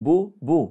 Likaså, när en person säger بُ بُ (BuBu)